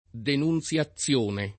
denunZLaZZL1ne] — quasi solo denuncia nel sign. di «disdetta»: d. di un accordo, e simili